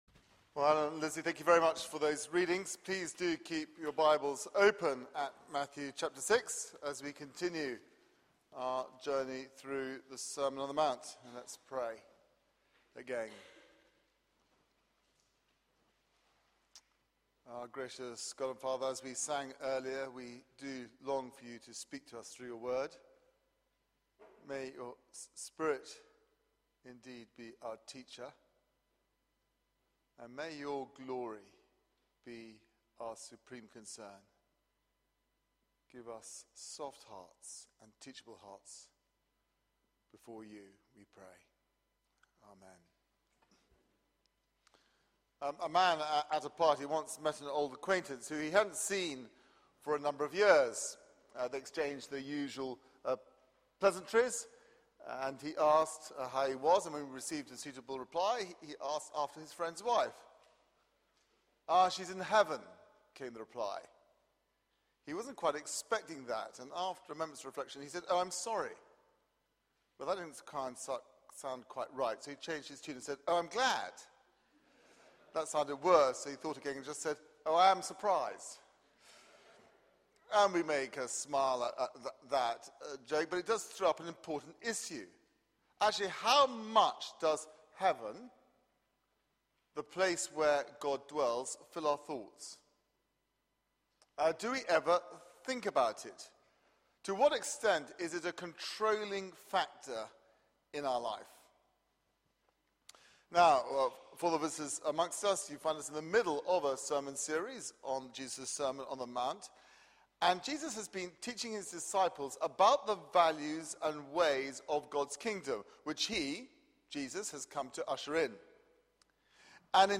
Media for 6:30pm Service on Sun 09th Jun 2013
Series: The Masterclass: The Sermon on the Mount Theme: Treasure in heaven